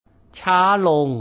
If they appear to speed or travel to fast, I tell them to slow down. ("chaa-lohng"